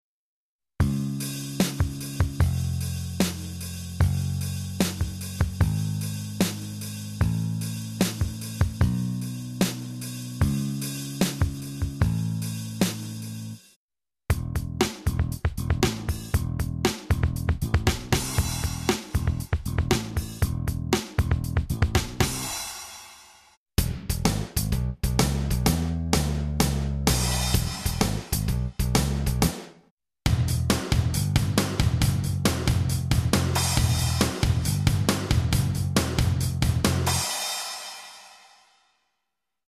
Elle fait batterie et basse.